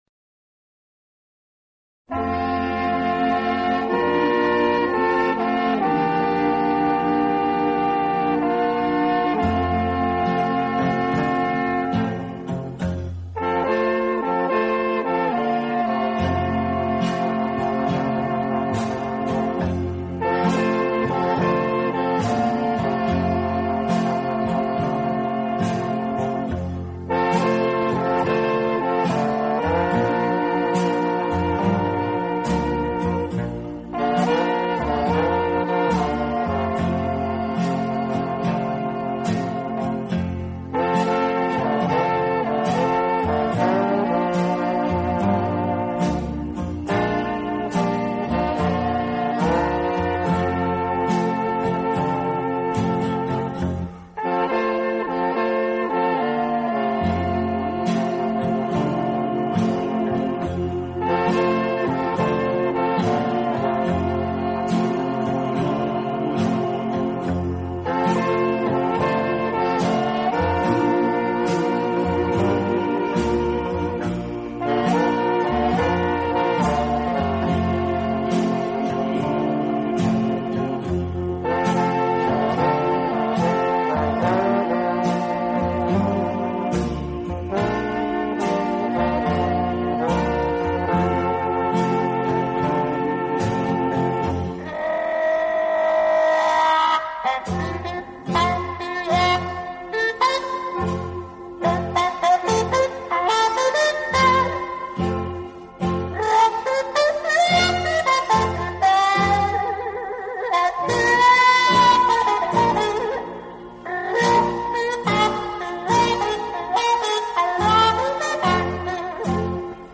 小号的音色，让他演奏主旋律，而由弦乐器予以衬托铺垫，音乐风格迷人柔情，声情并
温情、柔软、浪漫是他的特色，也是他与德国众艺术家不同的地方。